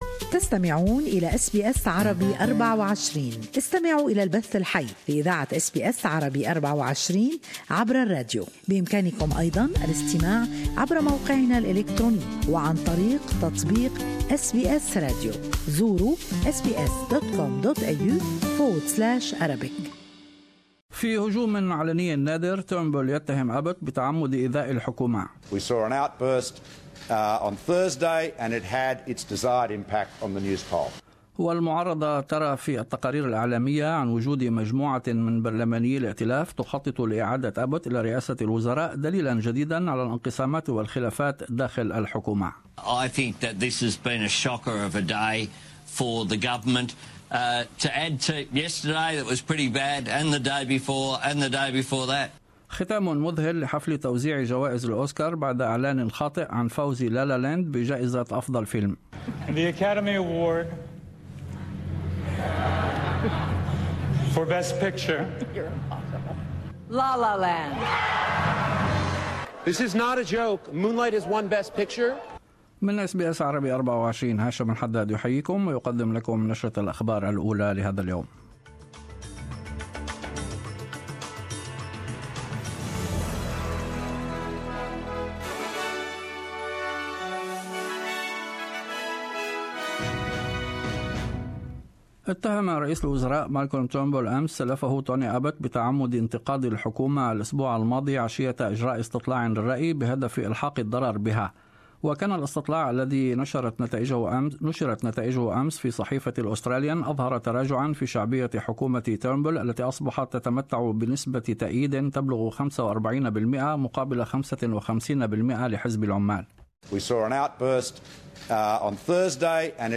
News Bulliten 28-2-17